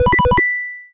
retro_beeps_collect_item_01.wav